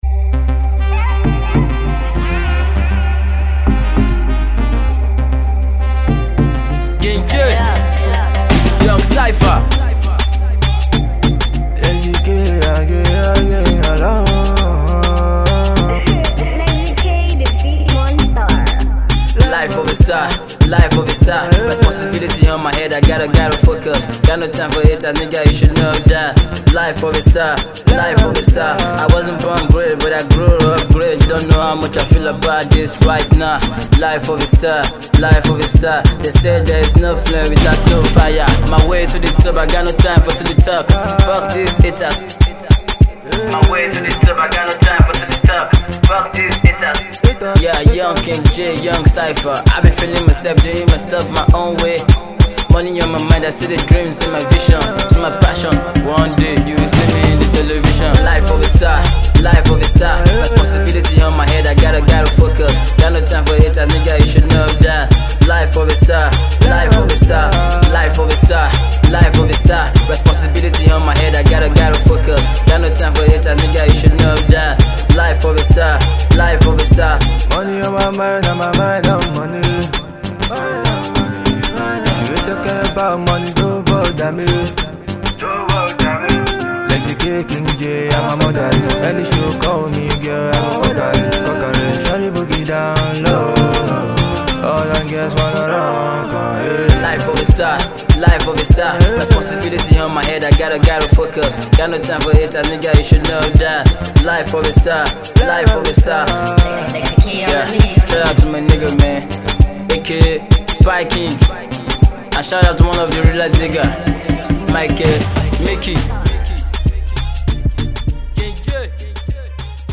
he split up bars punchline and rhymes